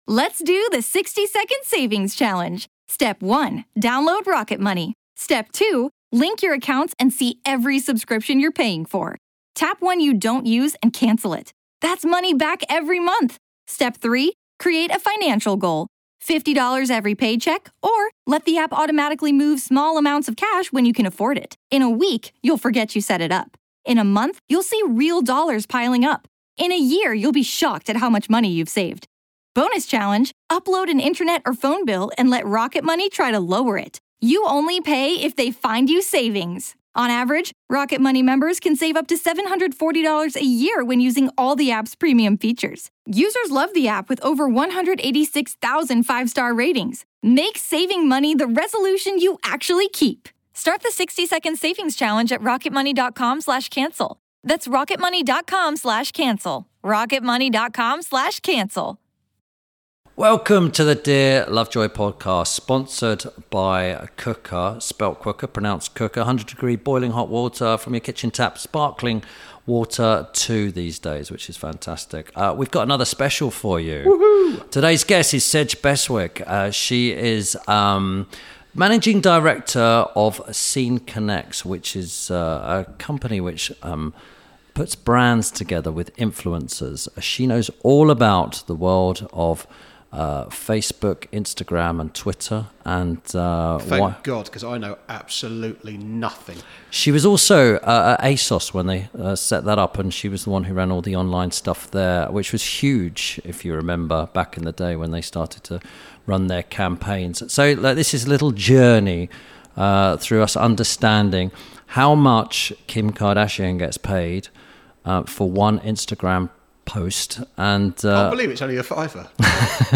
– INTERVIEW SPECIAL